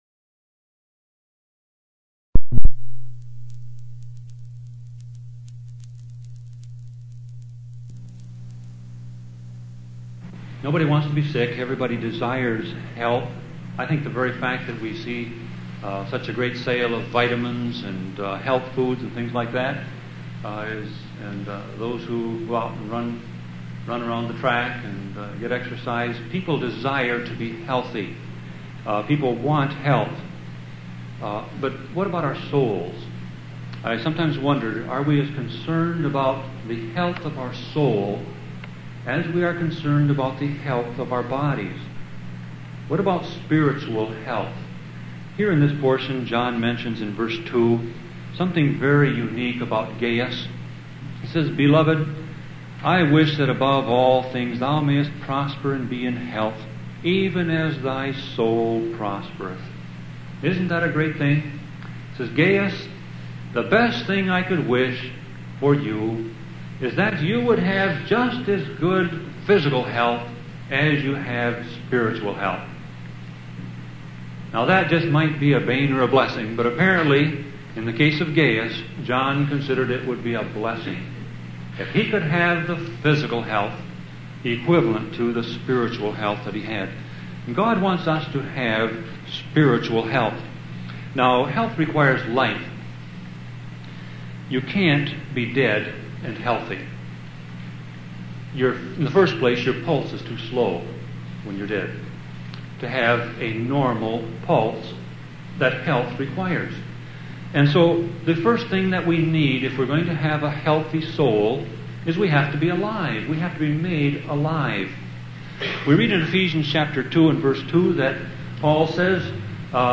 Sermon Audio Passage: 3 John 1:14 Service Type